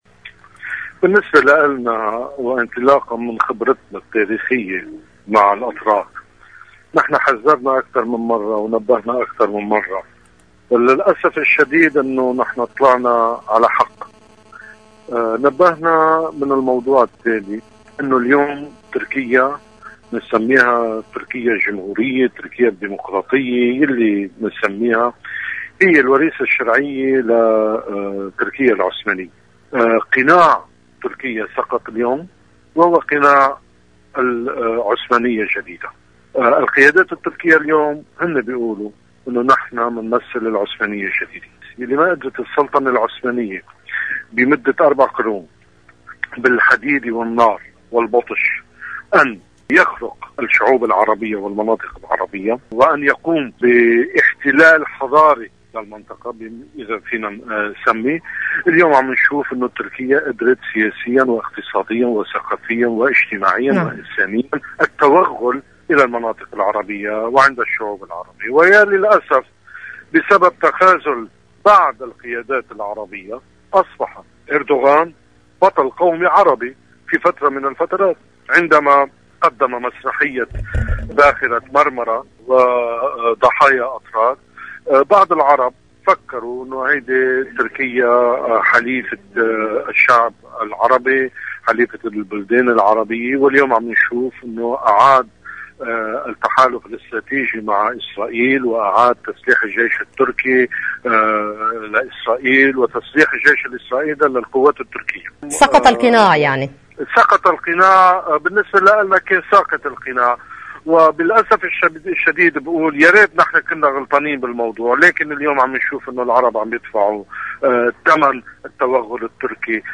النائب أغوب بقرادونيان لبرنامج "شو عم بصير": سقط القناع عن الدور التركي الحقيقي في المنطقة بعد انتهاء مسرحية سفينة مرمرة وإعادة الاعلان عن علاقاتها الاستراتيجية مع اسرائيل.
حقلة "الابادة الارمنية"، 24 نيسان 2013، صوت المدى 92.5FM.